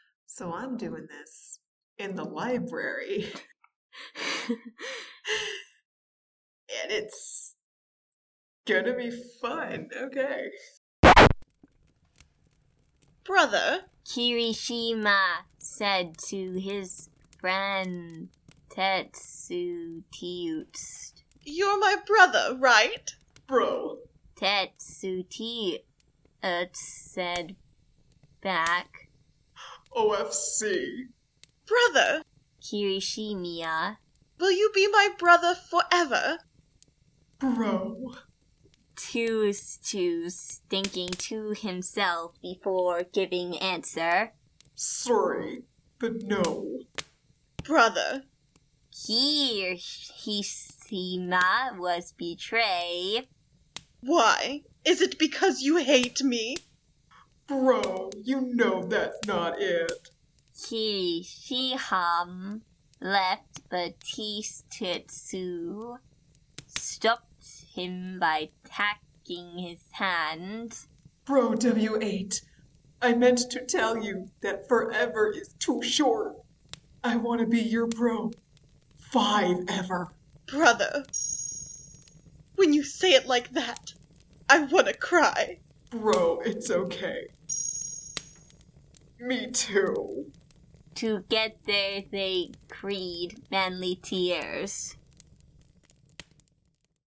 This is a crack podfic, meaning it's a joke parody of an otherwise not crack fic.
Voice of Eijiro Kirishima